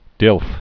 (dĭlf)